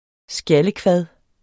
Udtale [ ˈsgjalə- ]